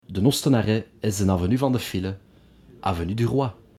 Les arrêts de la ligne de bus 48 annoncés en bruxellois
Les arrêts à bord de la ligne de la société bruxelloise de transport public (STIB) seront en effet déclamés en authentique bruxellois.
13. koningslaan.mp3